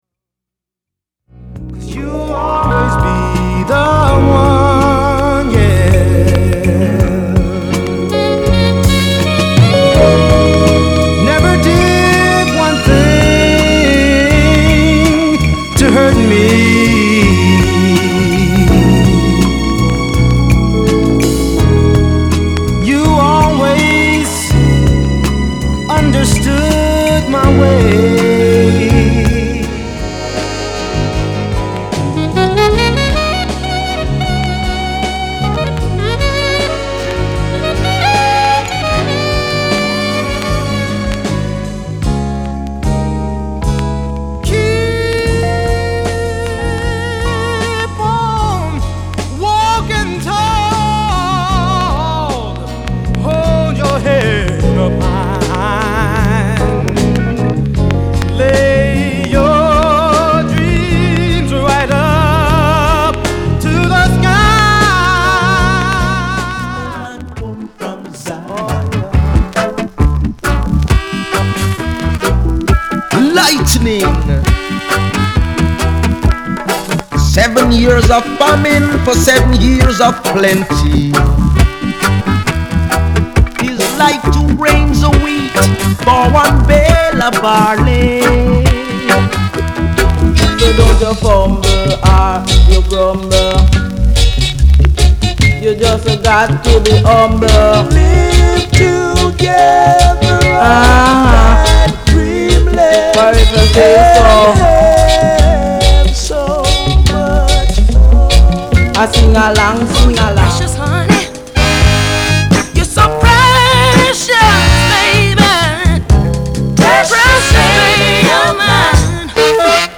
/盤質/両面やや傷あり再生良好です/US PRESS
類別 R&B、靈魂樂